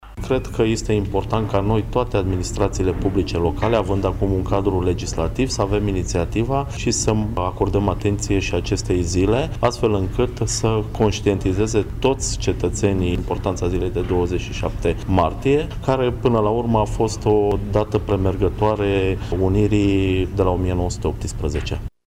Astfel, la sala de consiliu a Primăriei Braşov s-a organizat o conferinţă de presă, la care au participat viceprimarul Mihai Costel, precum şi reprezentanţi ai Asociaţiei Tinerilor Basarabeni şi cei ai platformei ,,Acţiunea 2012”, care militează pentru unirea Republicii Moldova cu România.
Viceprimarul a subliniat importanţa evenimentului din 27 martie şi faptul că această zi a devenit, din acest an, Sărbătoare Naţională în România: